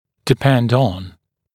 [dɪ’pend ɔn][ди’пэнд он]зависеть от